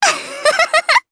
Rehartna-Vox_Happy2_jp.wav